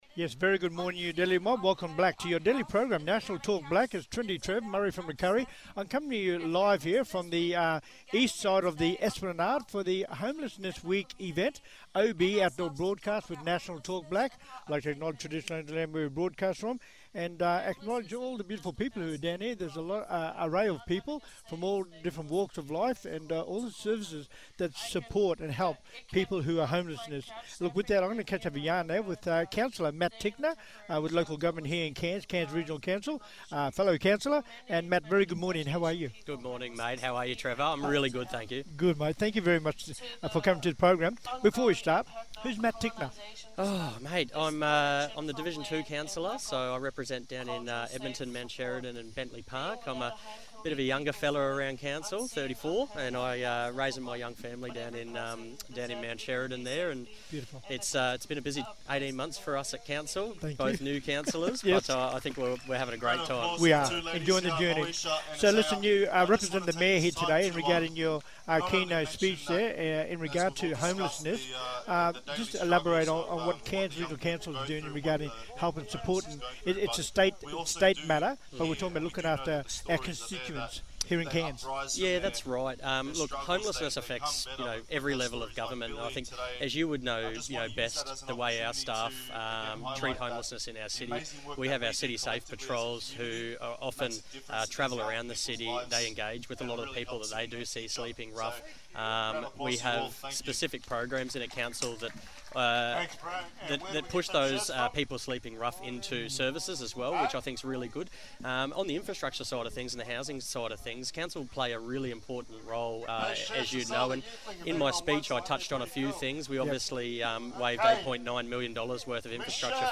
Special Guests Live today from the 2025 Cairns Homelessness Week Event.